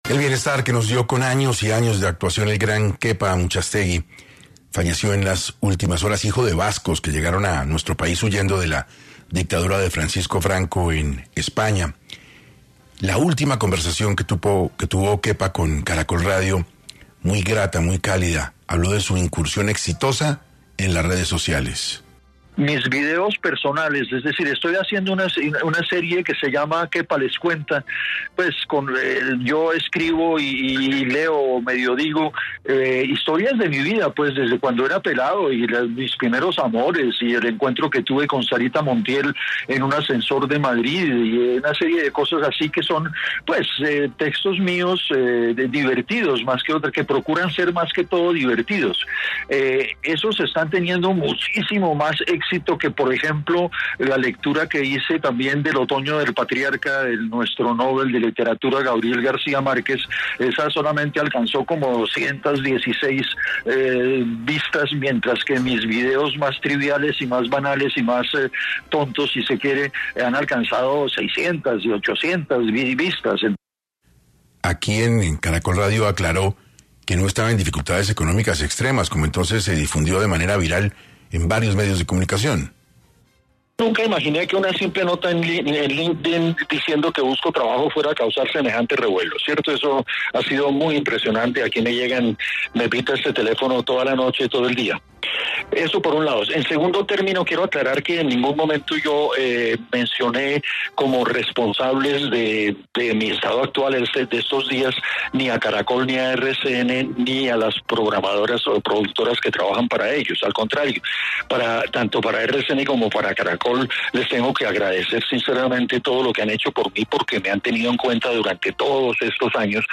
Homenaje a Kepa Amuchástegui
El 10 de agosto de 2020 fue la última conversación de Kepa con Caracol Radio.